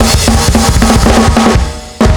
Index of /m8-backup/M8/Samples/musicradar-metal-drum-samples/drums acoustic/220bpm_drums_acoustic